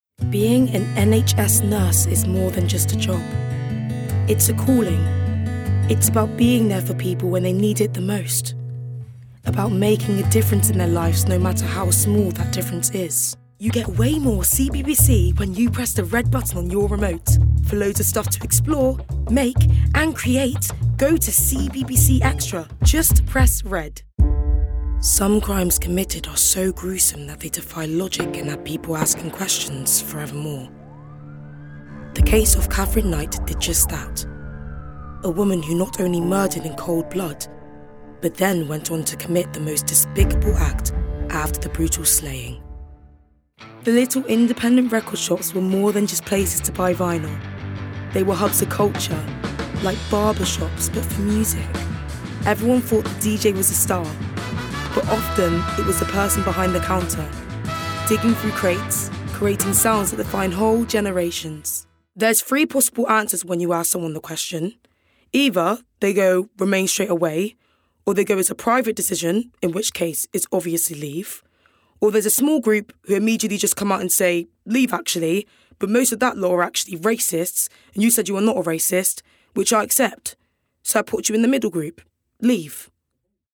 Bristolian
Voicereel:
BRITISH ISLES: Contemporary RP, Northern-Irish
GLOBAL: American-Standard, American-Southern States